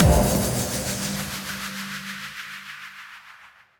Impact 10.wav